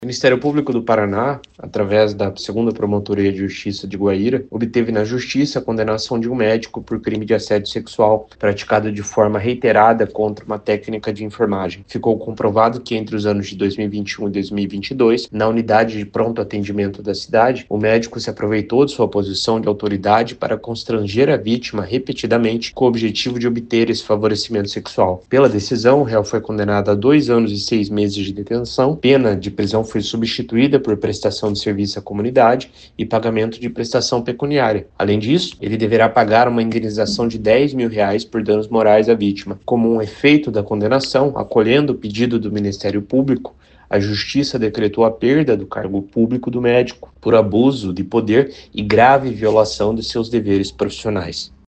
O promotor de Justiça Renan Góes de Lima diz que o assédio era praticado de forma reiterada.